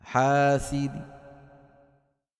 När man stannar med Rawm på en bokstav som har en tanwin av đammah eller en tanwin av kasrah, utelämnar vi tanwin och stannar med en del av đammahen på bokstaven med đammah och med en del av kasrahen på bokstaven med kasrah, som i: